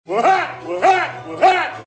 risa-it-el-payaso.mp3